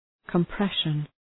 {kəm’preʃən}